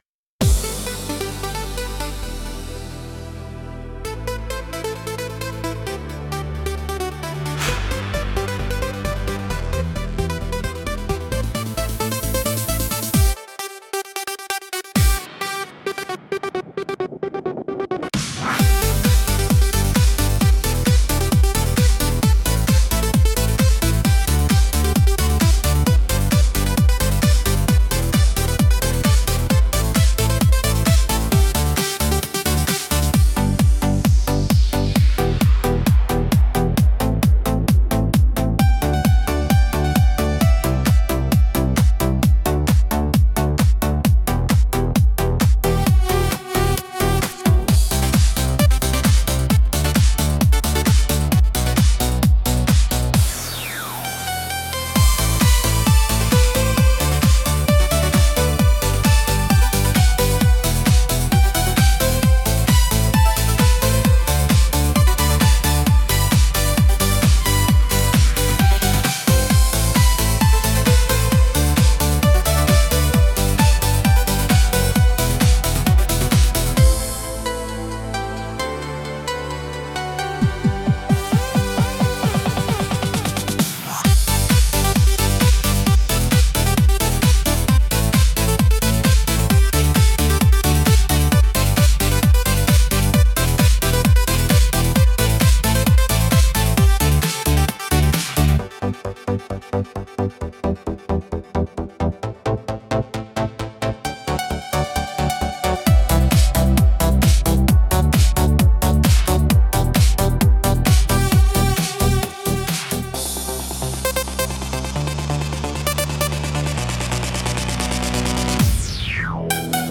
Instrumental - Kitschy Circuit 2.51